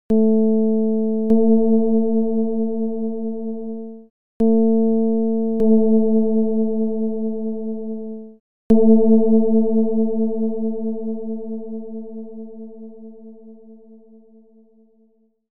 Ji-49-48-csound-foscil-220hz.mp3 (file size: 367 KB, MIME type: audio/mpeg)